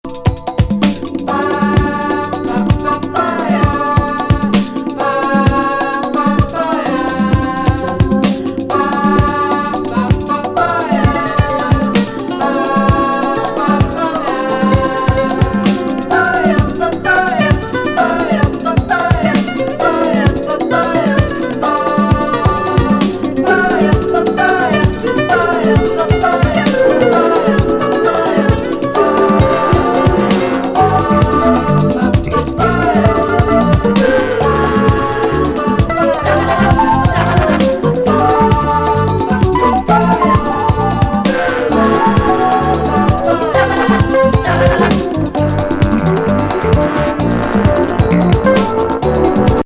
トロピカル・フュージョン
+スムースパヤパヤ・コーラスで◎!